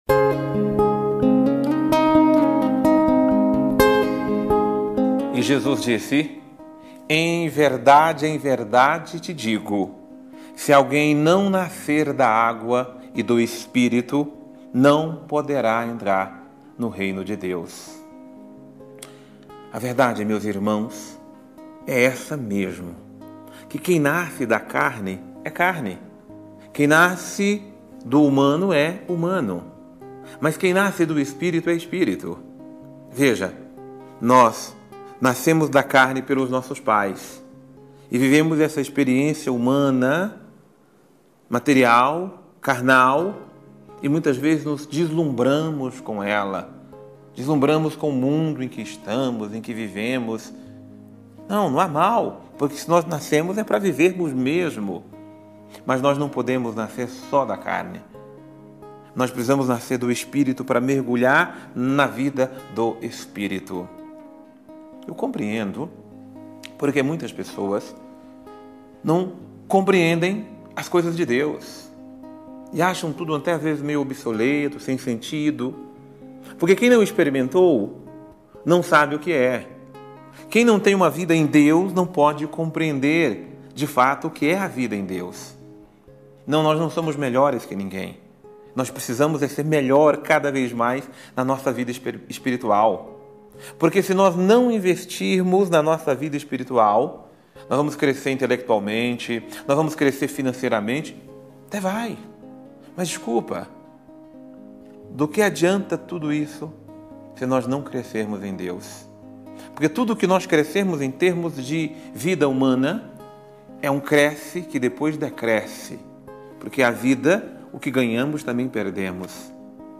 Confira a Homilia diária - Nasçamos para as coisas do Alto